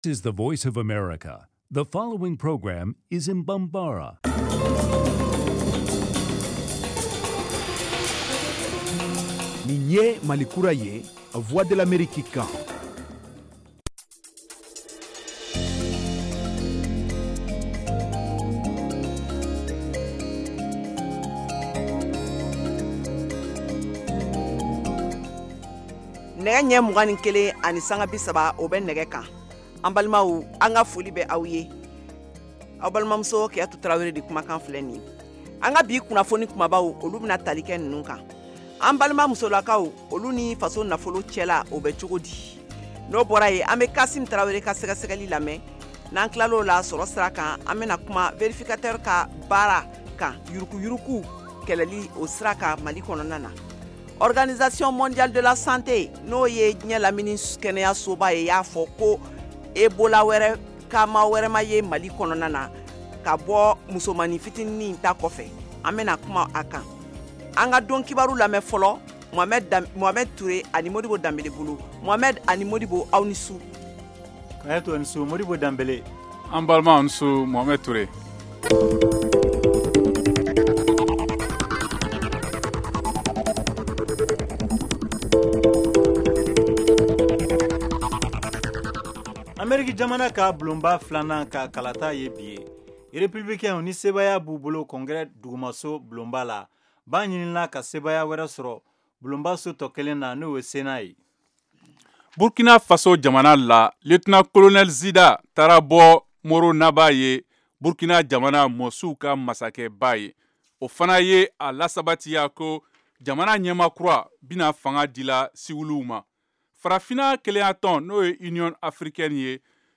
Emission quotidienne en langue bambara
en direct de Washington